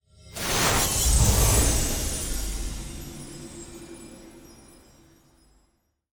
UI_Point_Door.ogg